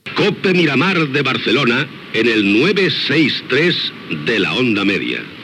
Indicatiu de l'emissora i freqüència d'emissió